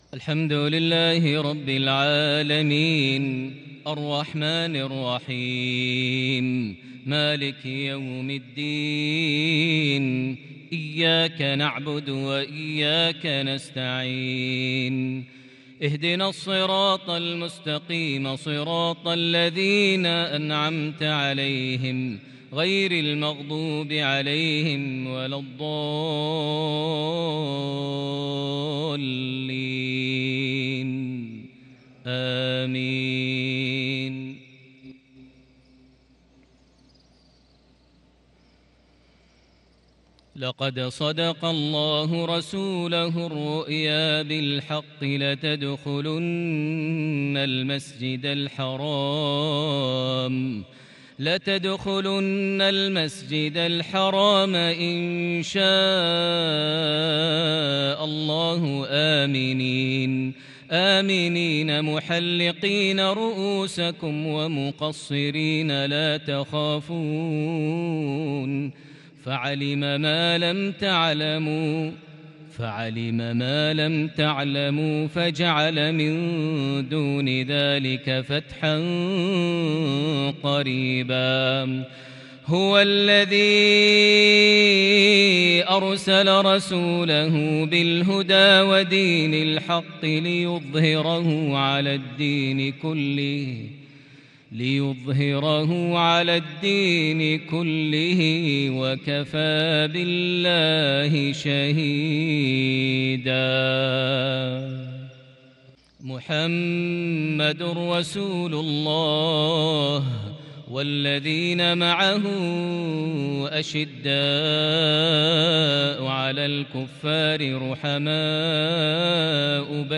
مغربية بديعة بالكرد الفذ لخواتيم سورة الفتح (27-29) | الجمعة 16 شوال 1442هـ > 1442 هـ > الفروض - تلاوات ماهر المعيقلي